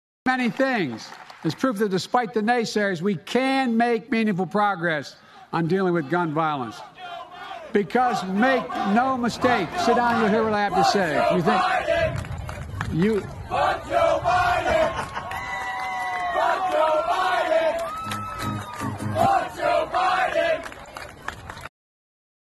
Biden hält eine Rede und wird von den Bürgern mit Fuck you Biden-Sprechchören konfrontiert, was ihn sichtlich irritiert...